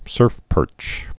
(sûrfpûrch)